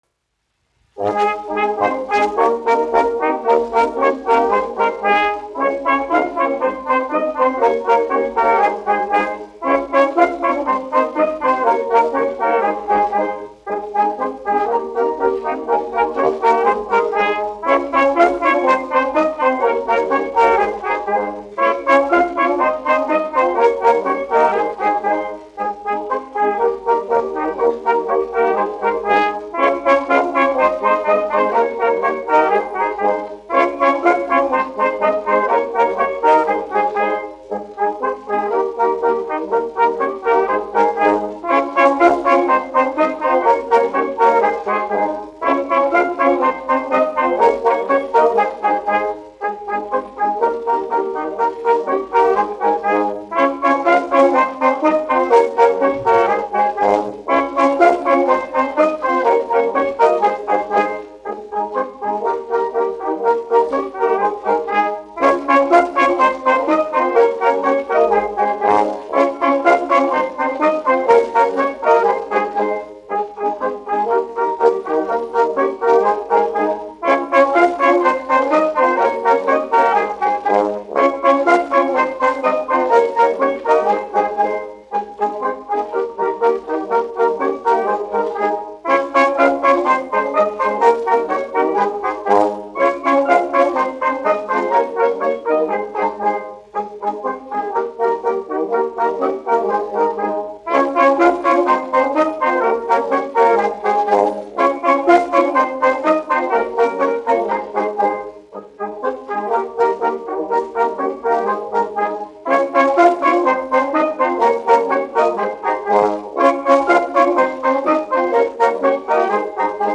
1 skpl. : analogs, 78 apgr/min, mono ; 25 cm
Latviešu tautasdziesmas
Pūtēju orķestra mūzika
Skaņuplate